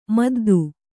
♪ maddu